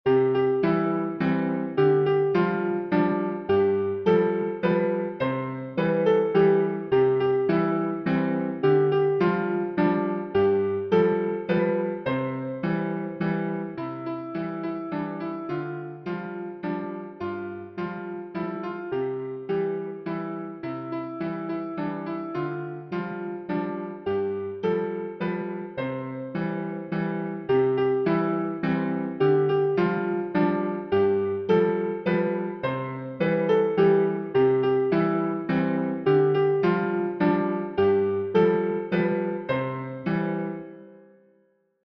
Аннушка Чешская народная песня